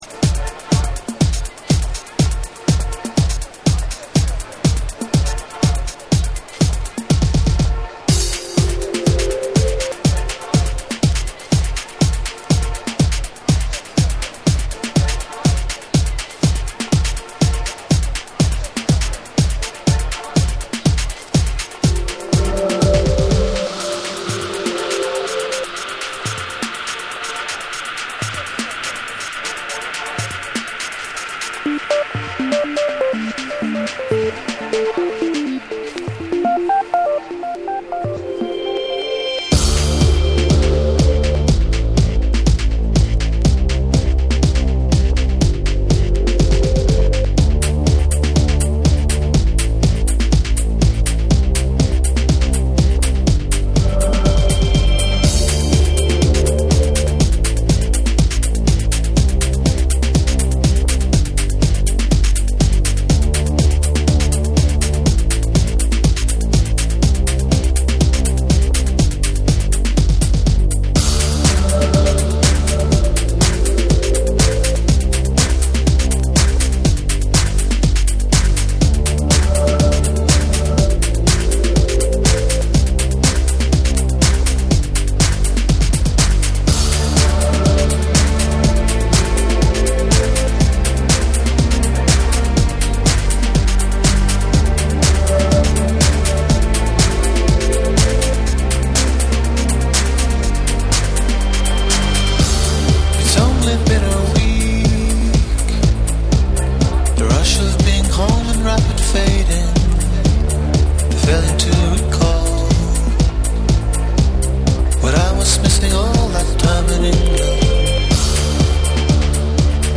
キャッチーでエレクトリックなハウス・ナンバーを収録！
TECHNO & HOUSE